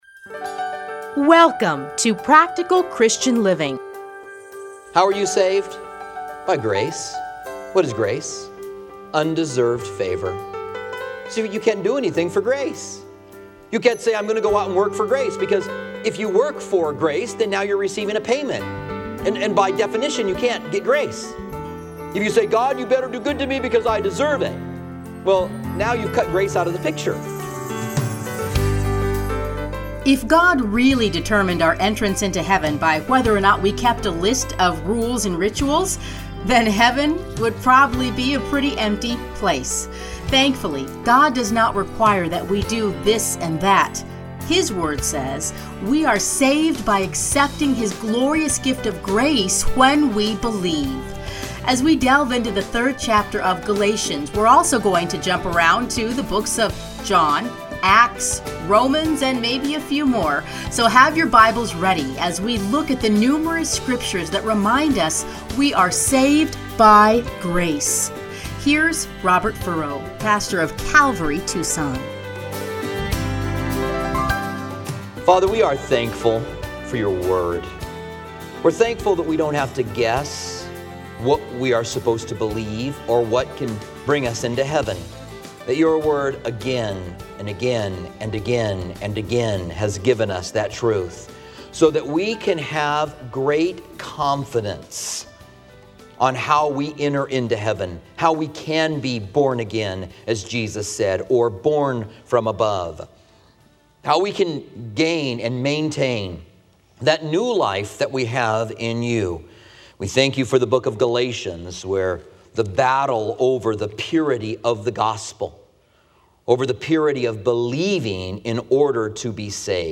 Listen here to his commentary on Galatians. Playlists Commentary on Galatians Download Audio